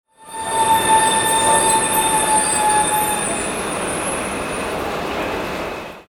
Subway Train Stopping With Screeching Sound Effect
Realistic sound effect of a subway train slowing down and stopping with loud wheel screeches. Perfect for adding authentic metro ambience to films, games, animations, or video projects that need an urban underground railway atmosphere.
Subway-train-stopping-with-screeching-sound-effect.mp3